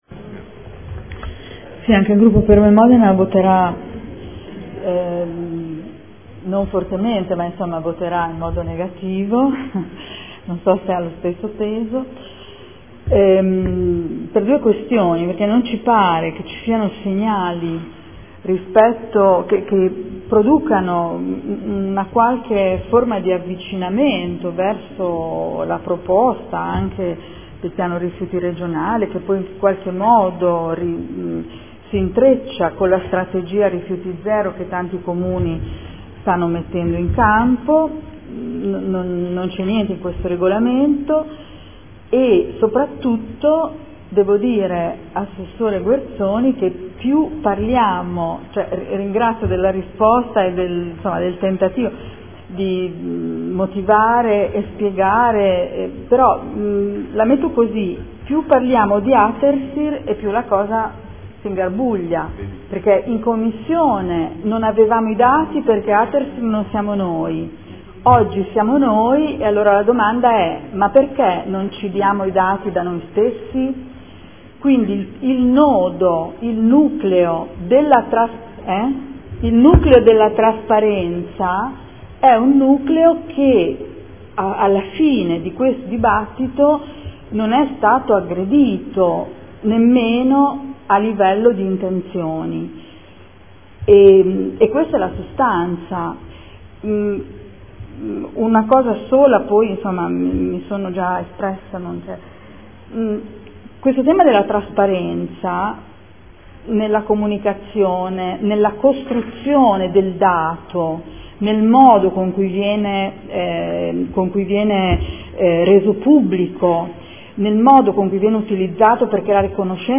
Adriana Querzè — Sito Audio Consiglio Comunale
Seduta del 24/07/2014. Dichiarazione di Voto.